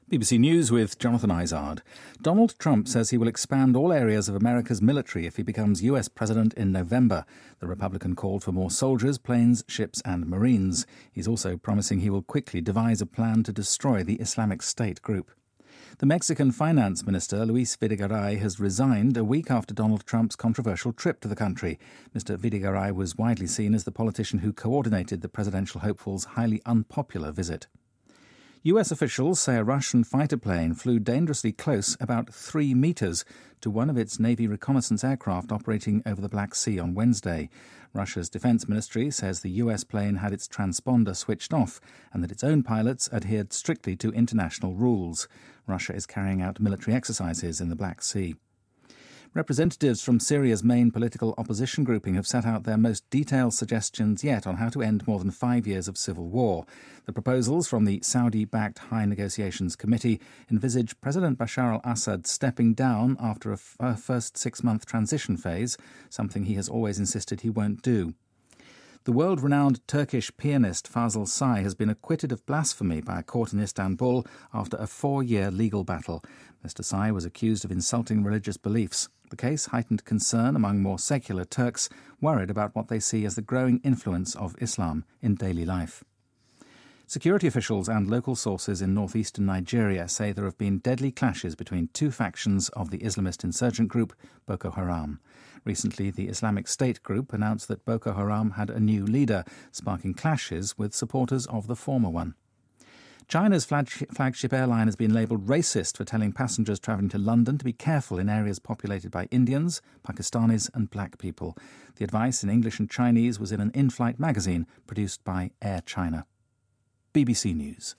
BBC news,墨西哥财长因策划特朗普访墨卸任